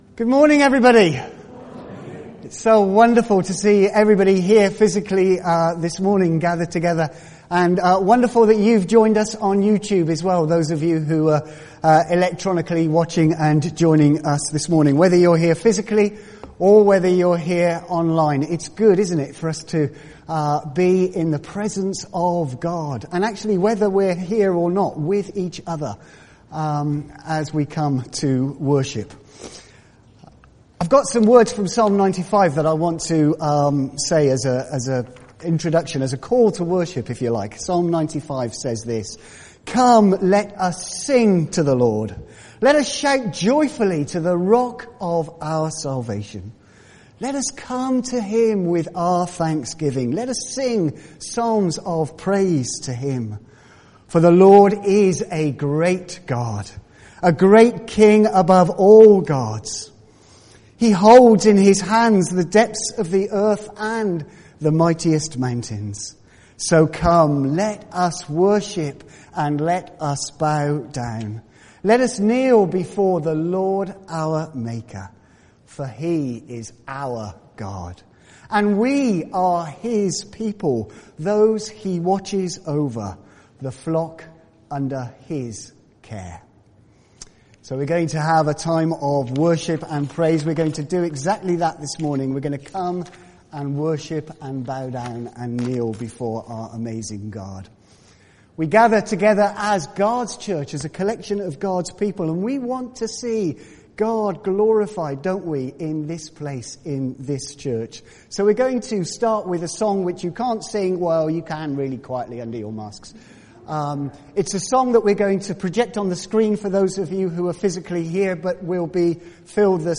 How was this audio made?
From Service: "10.30am Service"